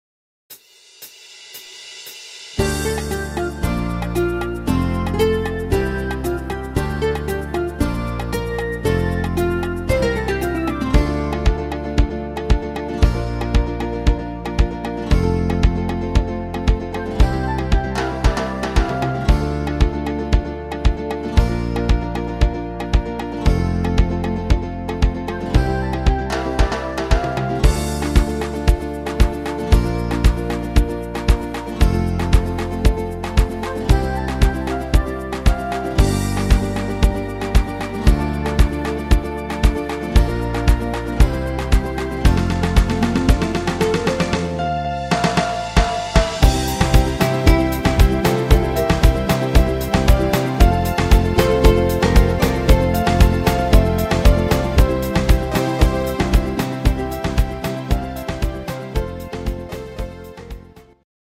ein super Rhythmus